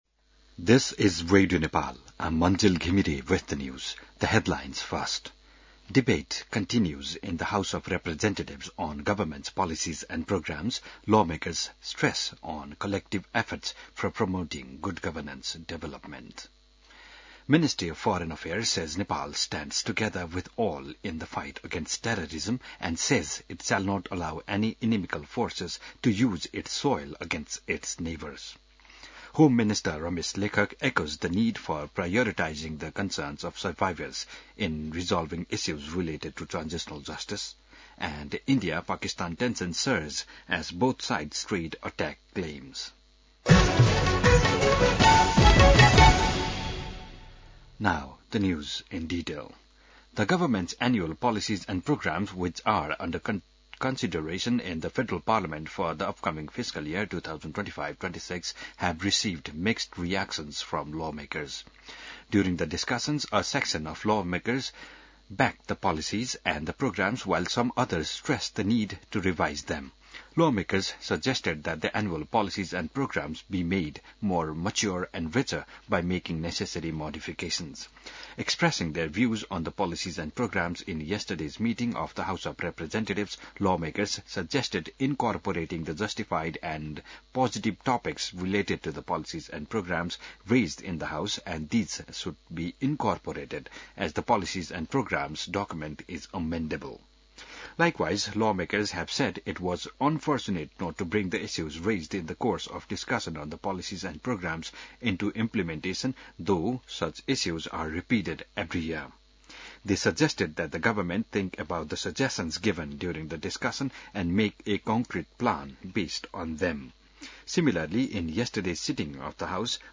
बिहान ८ बजेको अङ्ग्रेजी समाचार : २६ वैशाख , २०८२